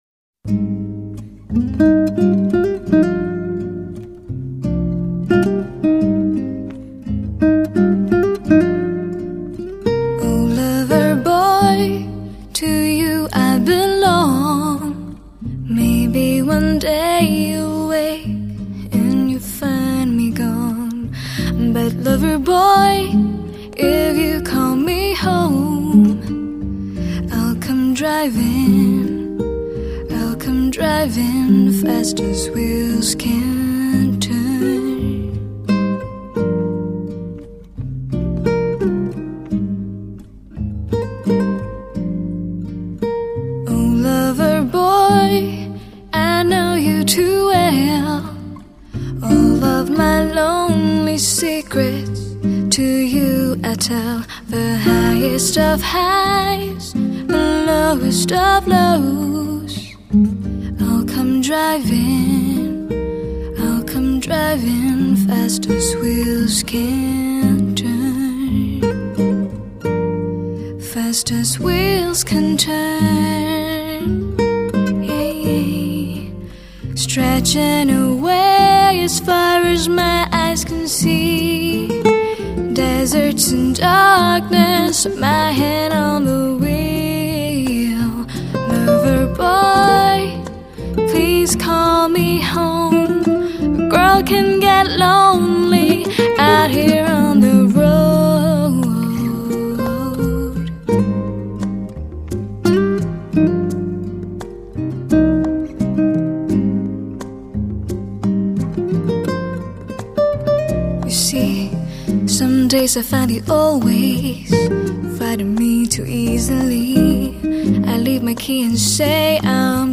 曲风是爵士Bossa Nova
淡淡的隐约有张力的音质
我激动了 这个女生的声音太好听了！！！！！！
听到吉他了 她是自弹自唱吗 她是原创歌手咩~~~~那样就真的太完美了！！！！！